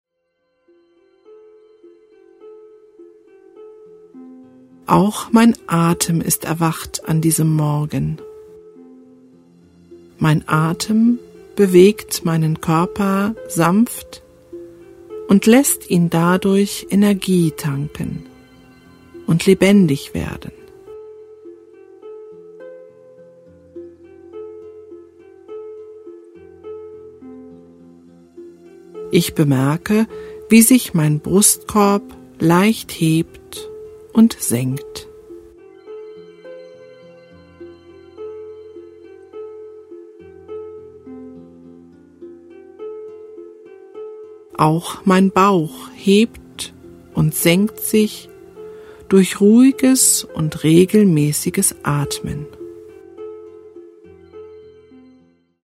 Weibliche Stimme   10:28 min
Die begleitende Musik beginnt ruhig und wird nach und nach belebter.